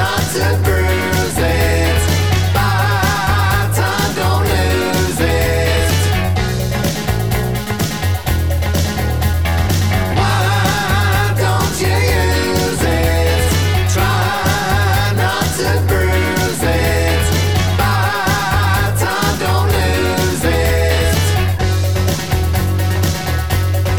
One Semitone Down Pop (1980s) 4:44 Buy £1.50